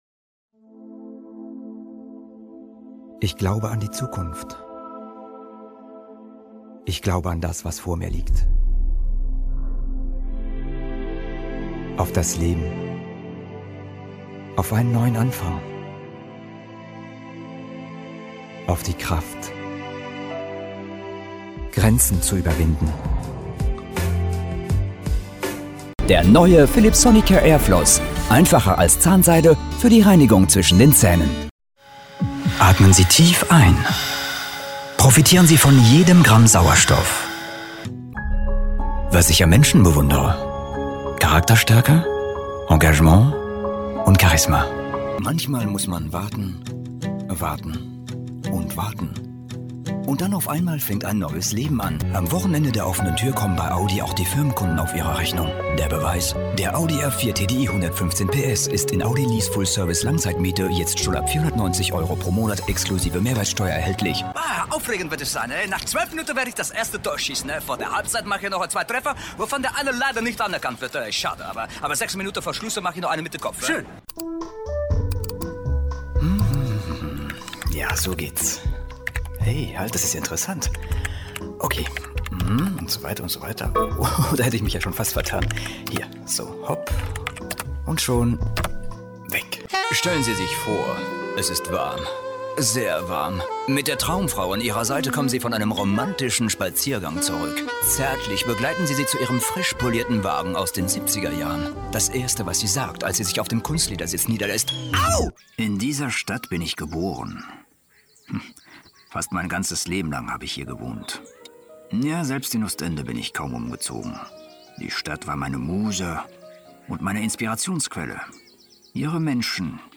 German male versatile professional voice over talent and actor - Fluent in French
Kein Dialekt
Sprechprobe: Sonstiges (Muttersprache):